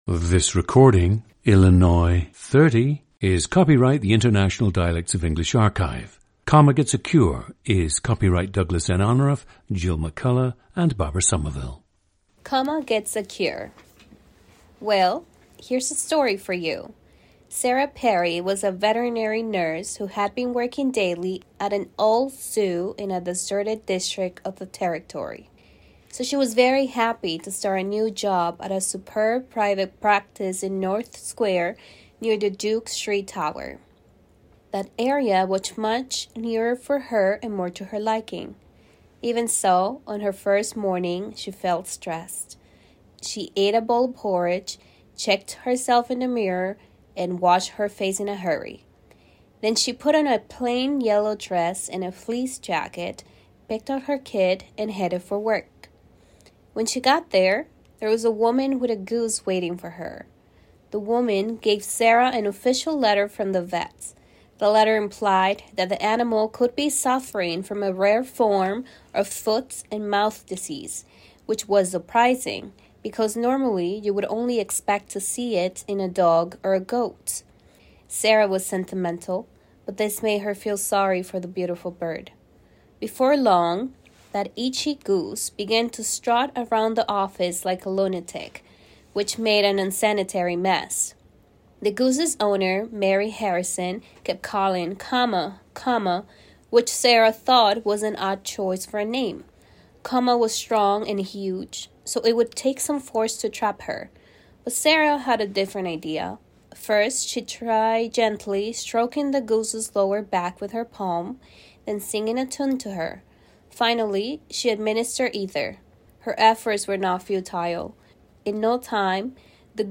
GENDER: female
The subject’s accented English reflects her experience as a native speaker of Spanish, as well as her time living in the Midwest and her voice-and-speech classes taken in college.
• Recordings of accent/dialect speakers from the region you select.
The recordings average four minutes in length and feature both the reading of one of two standard passages, and some unscripted speech.